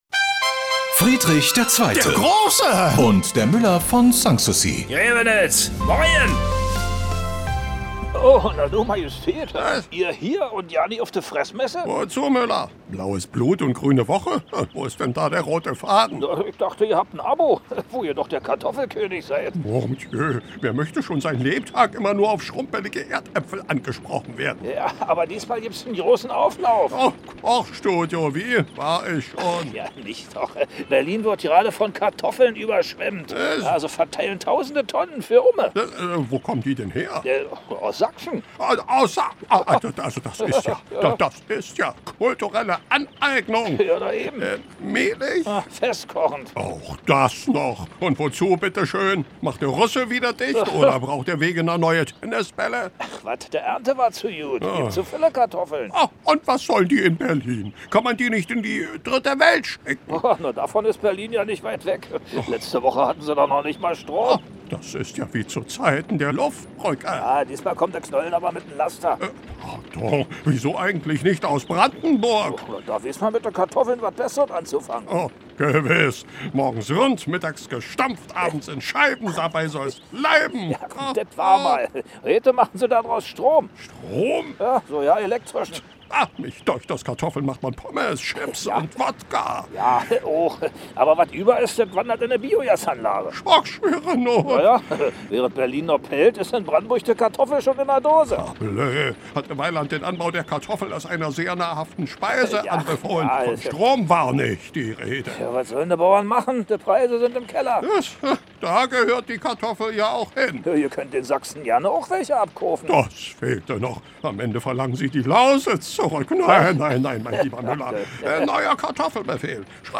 Der legendäre Nachbarschaftsstreit setzt sich bis heute fort: Preußenkönig Friedrich II. gegen den Müller von Sanssouci. Immer samstags kriegen sich die beiden bei Antenne Brandenburg in die Haare.
Regionales , Comedy , Radio